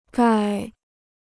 钙 (gài): calcium (chemistry).